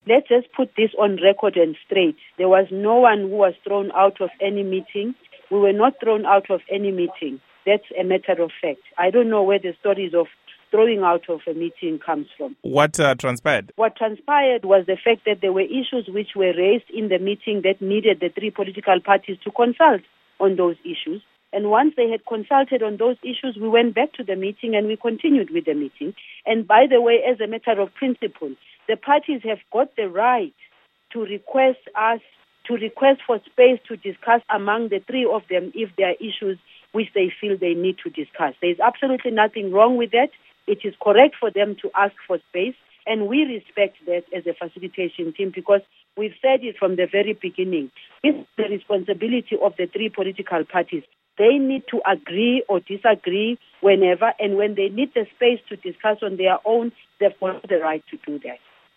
But Mr. Zuma’s international relations advisor Lindiwe Zulu told VOA's Studio that there was no such impasse.
Interview With Lindiwe Zulu